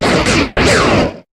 Cri de Zygarde dans sa forme 10 % dans Pokémon HOME.